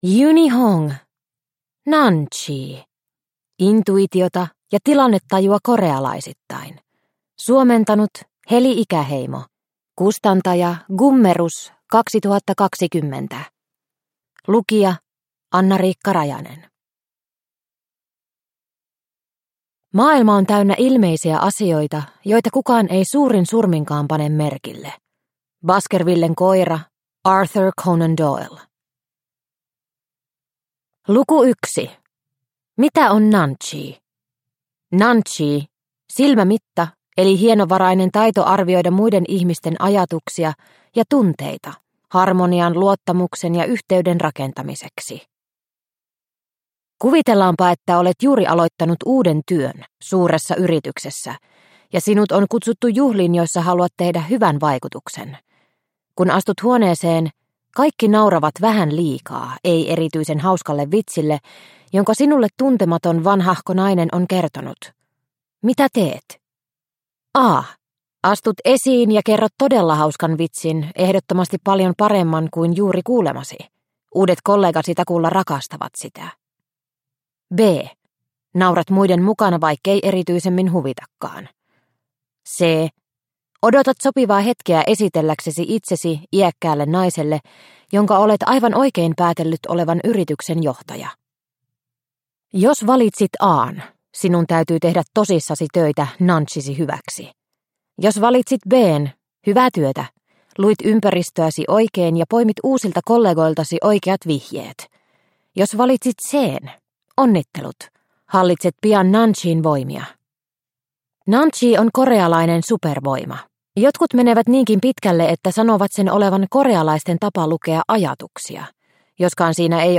Nunchi – Ljudbok – Laddas ner